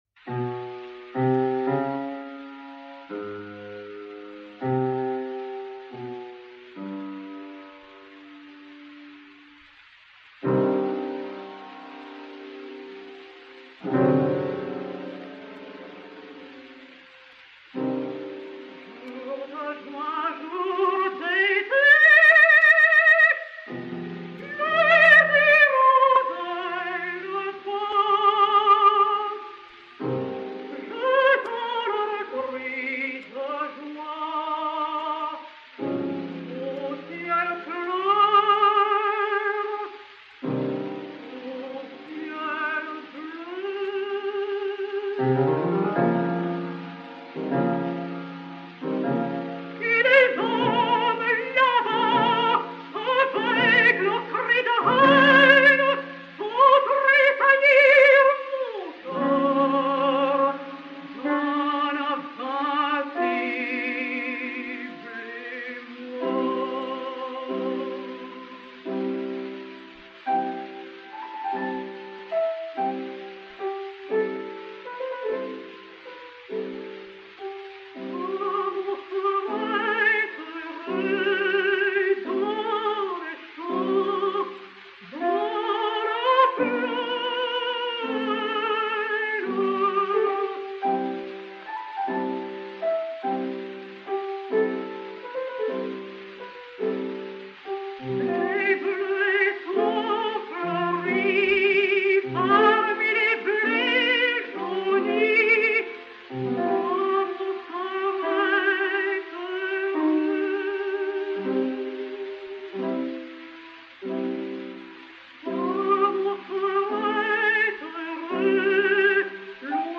piano
Pathé 3411, mat. 201971, enr. en 1927/1928